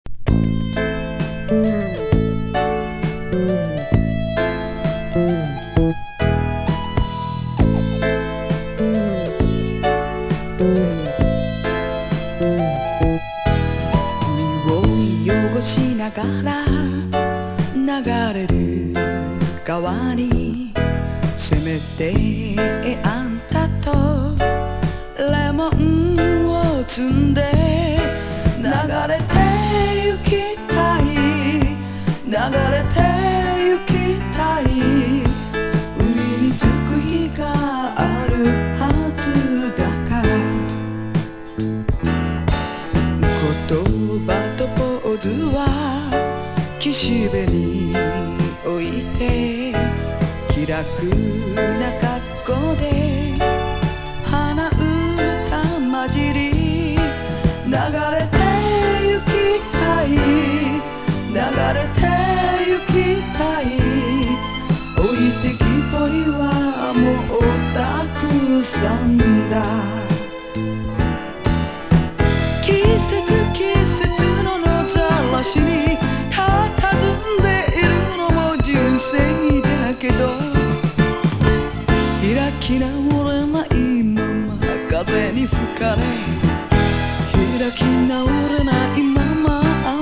シンガソングライター
SSW / FOLK# 和モノ / ポピュラー